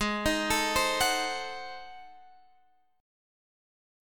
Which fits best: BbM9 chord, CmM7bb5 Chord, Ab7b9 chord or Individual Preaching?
Ab7b9 chord